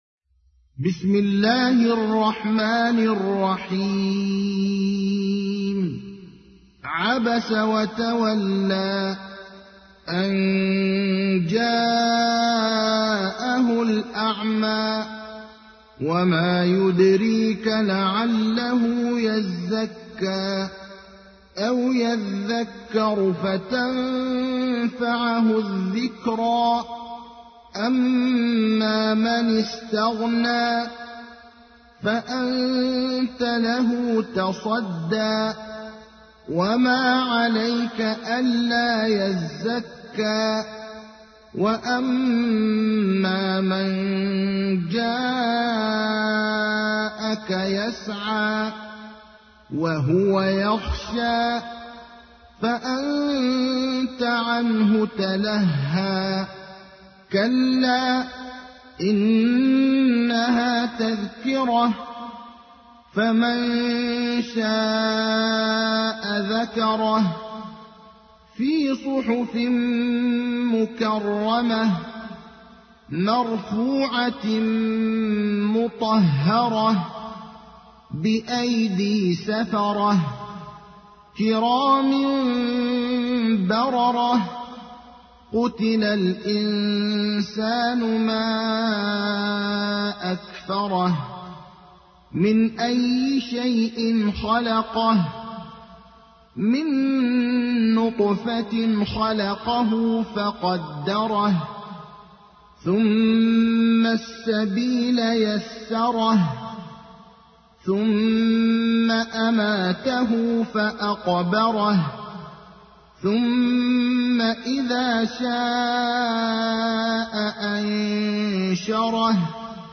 تحميل : 80. سورة عبس / القارئ ابراهيم الأخضر / القرآن الكريم / موقع يا حسين